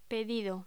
Locución: Pedido
voz